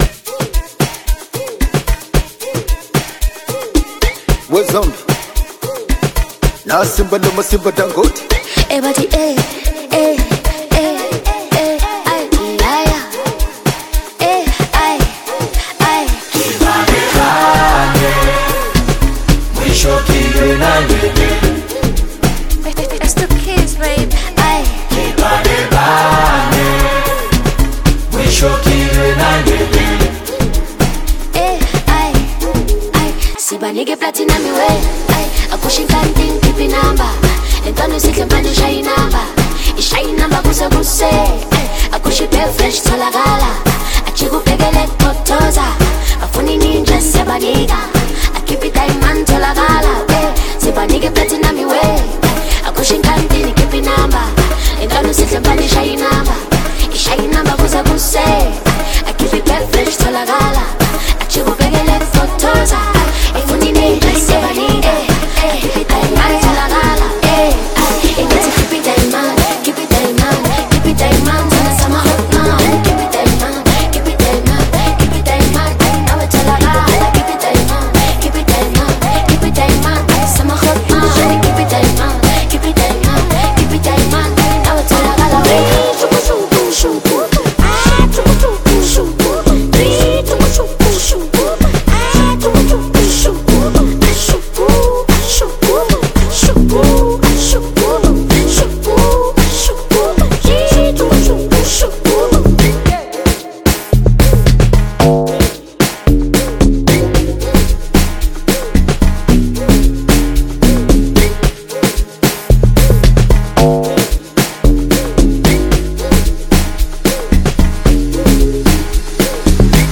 Her soulful vocals